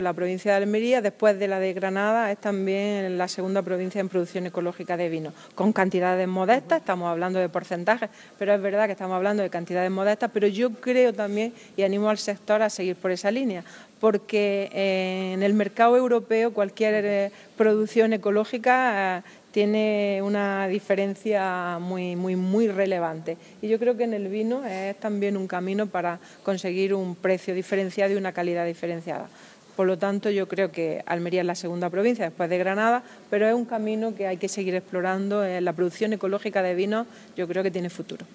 Declaraciones de Carmen Ortiz sobre vino ecológico